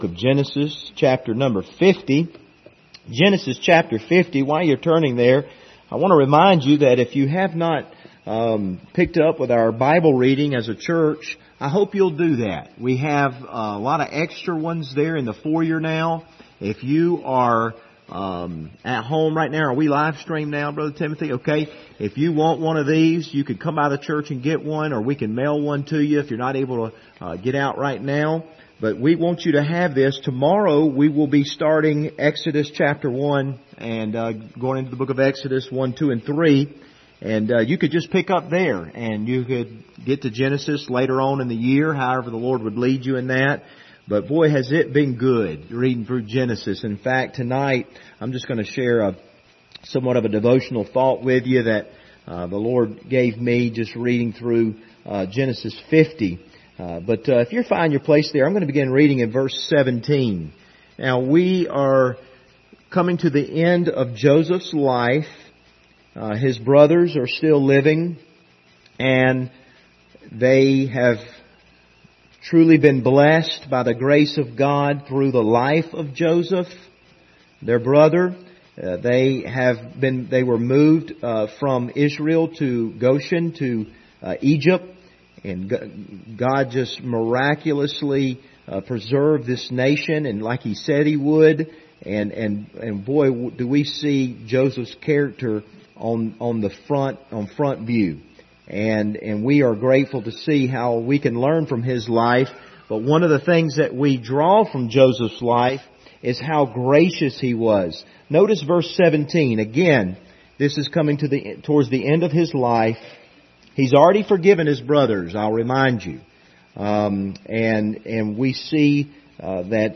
Genesis 50:17 Service Type: Wednesday Evening Topics: forgiveness